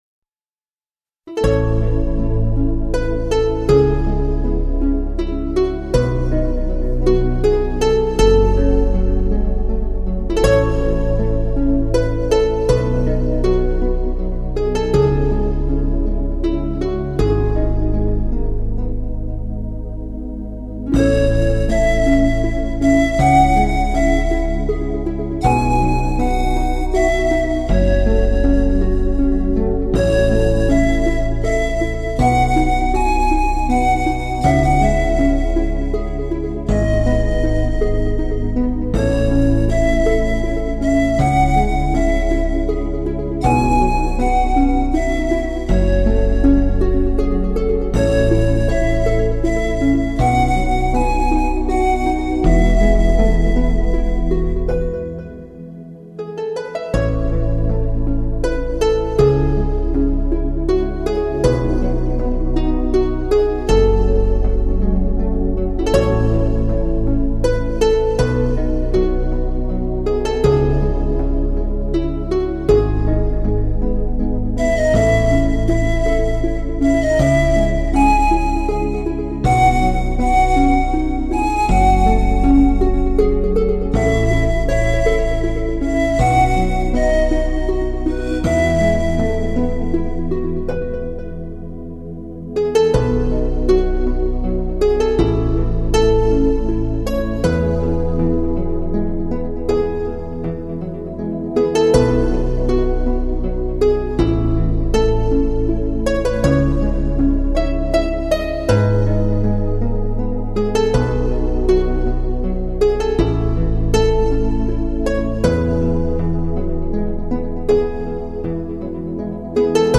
音乐，营造出虚无灵妙的大自然梦境……